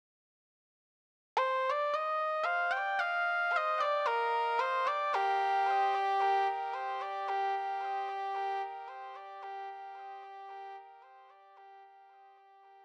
06 Solo Synth Bars end+tail.wav